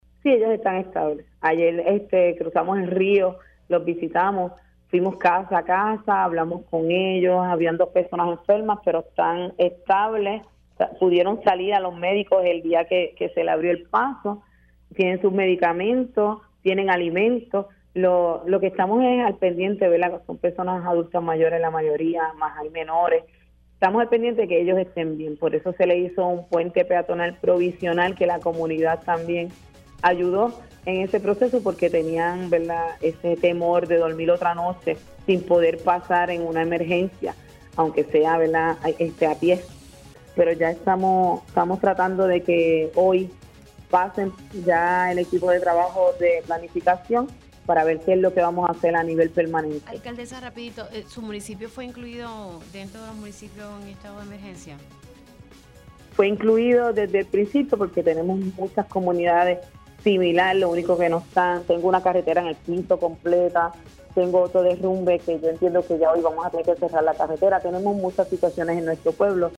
La alcaldesa de Aguas Buenas, Karina Nieves indicó en Pega’os en la Mañana que 26 familias de la comunidad Lupe Camacho están incomunicadas luego de que las intensas lluvias este fin de semana agravaron un socavón en una carretera del área.